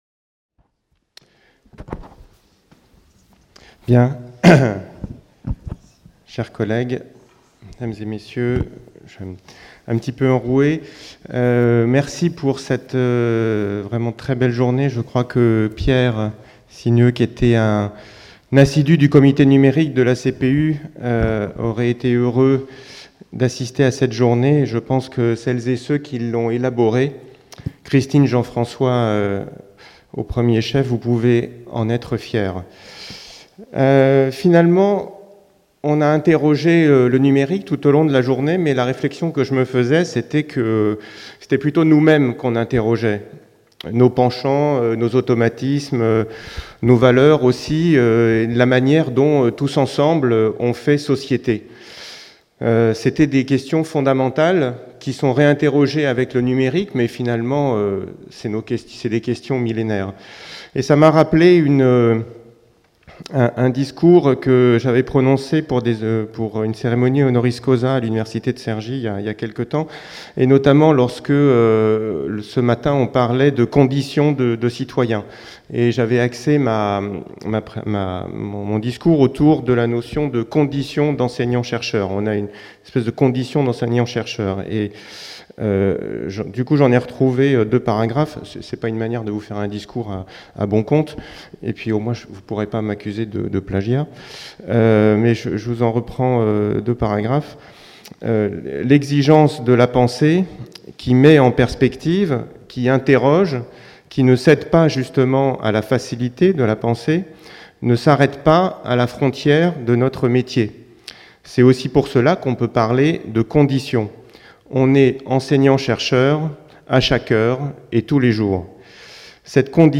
08 - Clôture de la Conférence des Présidents d'Université (cpucaen 2016) | Canal U